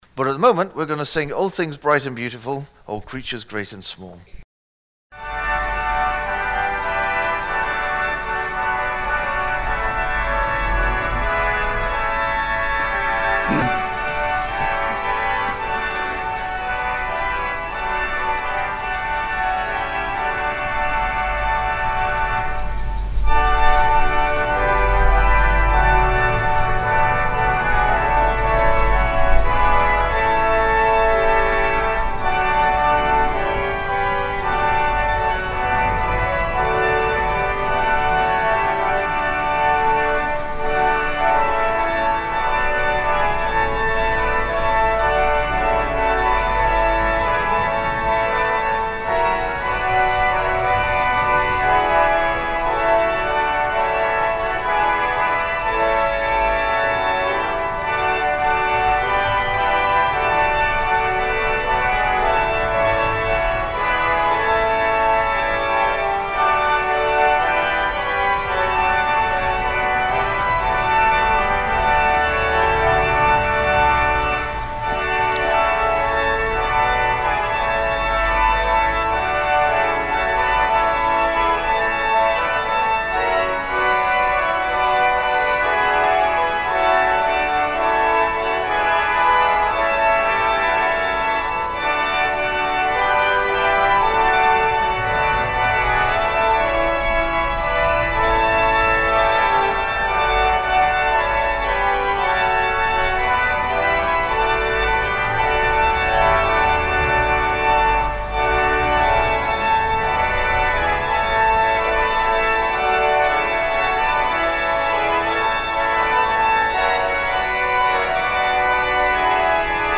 Waste Watchers Holiday Club Family Service